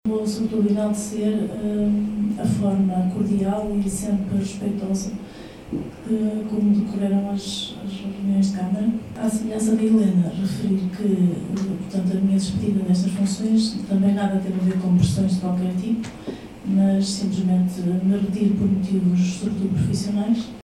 Despedidas, alguns aplausos e possíveis novos rostos a partir de outubro marcaram a última sessão da Assembleia Municipal de Paredes de Coura, na passada sexta-feira.
Por ser a última sessão deste mandato, tiveram direito a palavras de despedida.